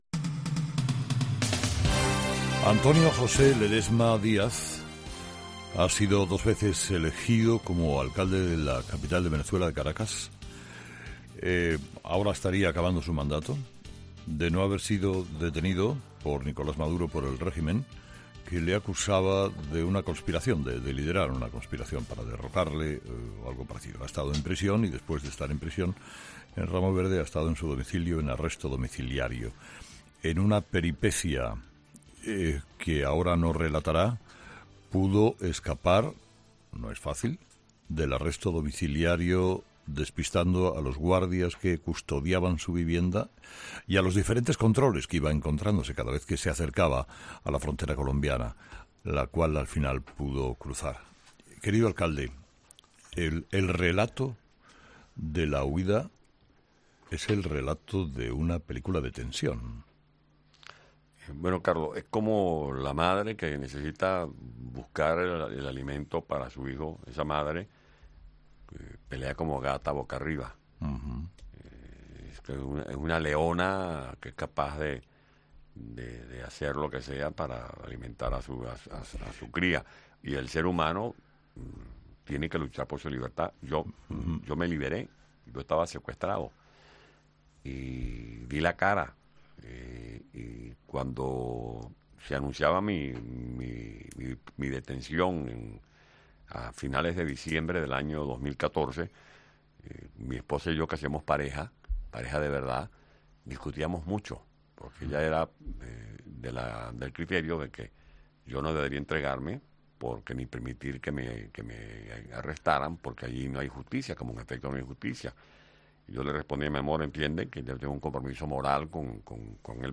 Escucha la entrevista al opositor venezolano Antonio Ledezma